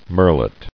[murre·let]